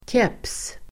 Ladda ner uttalet
Uttal: [kep:s]